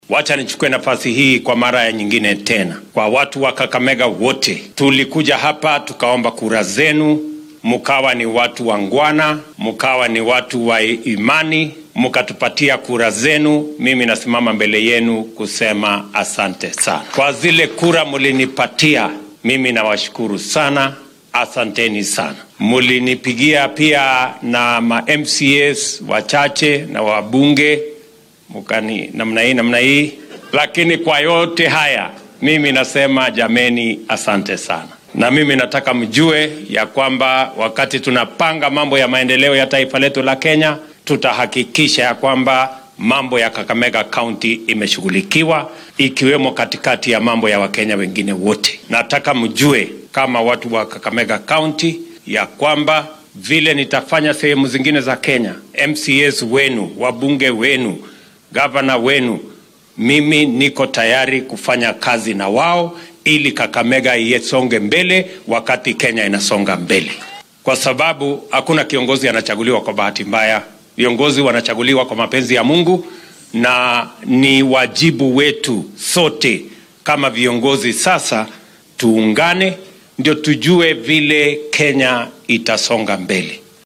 Waxaa uu kaniisad ku taalla deegaan baarlamaneedka Lurambi ka sheegay inuu diyaar u yahay la shaqeynta dhammaan madaxda la soo doortay .
William-Ruto-Kakamega.mp3